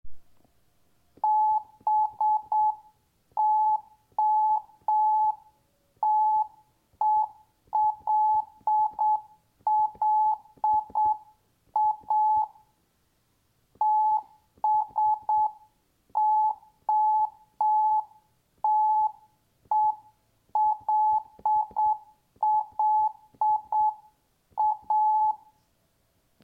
Botella en morse
Botella_Morse.mp3